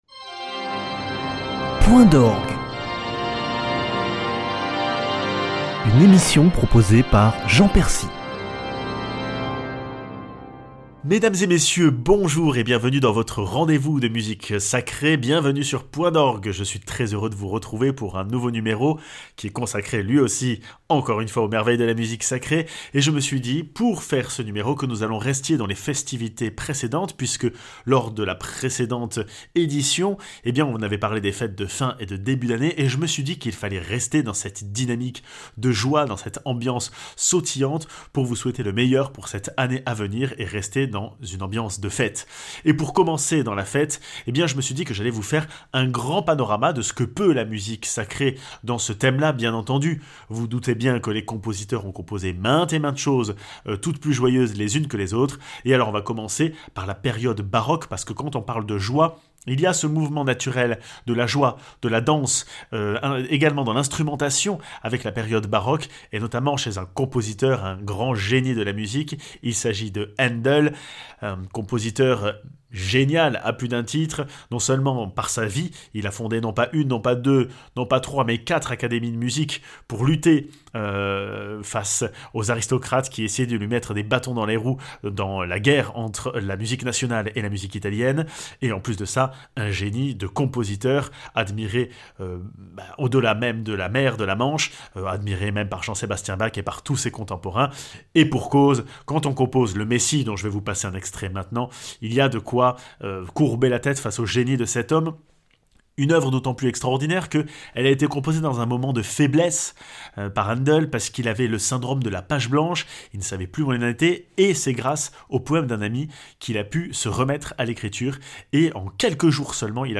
Afin de prolonger l’esprit des fêtes de fin d’année entrée dans le répertoire le plus festif de la musique sacrée ( Rejoice du Messie de Haendel, Transports de joie de Messiaen et Gloria de la messe Cum Jubilo de Duruflé)